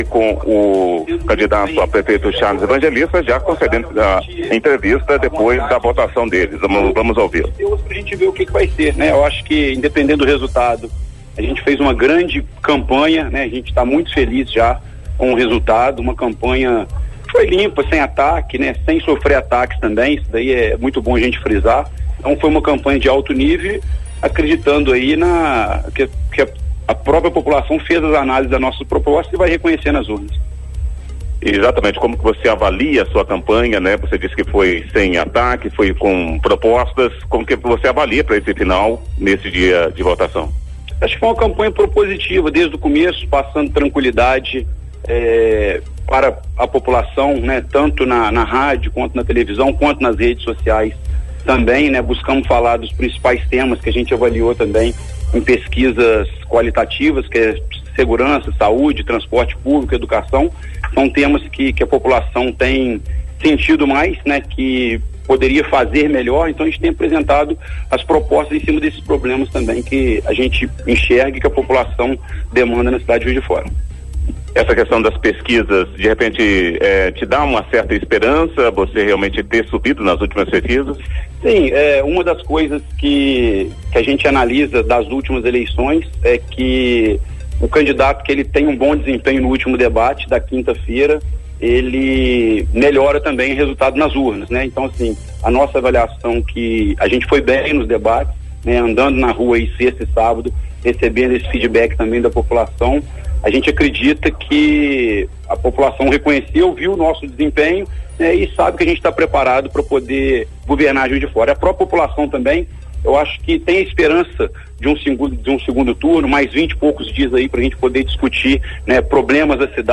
Após votar, Charlles Evangelista falou com a imprensa sobre a avaliação de campanha e expectativas para o 2º turno.